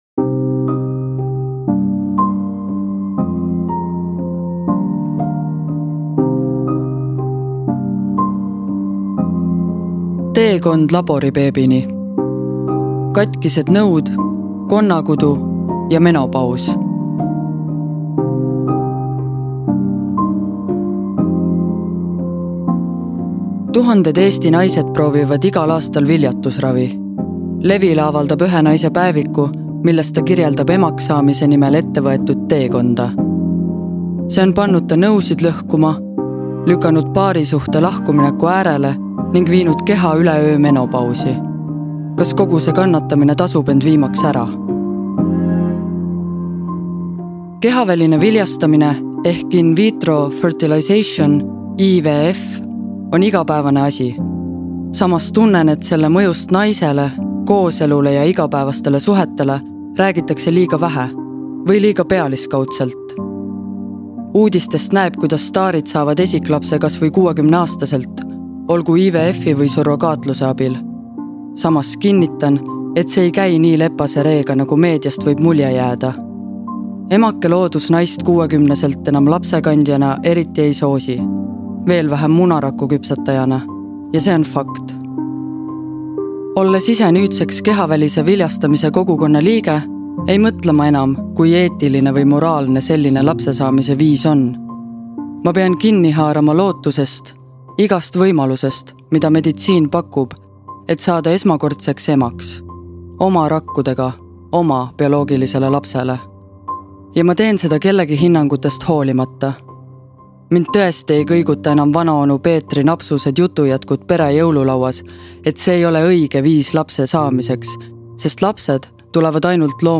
Audiolugu loeb